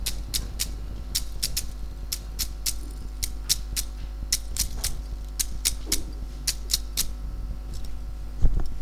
描述：玩具车。
标签： 玩具
声道立体声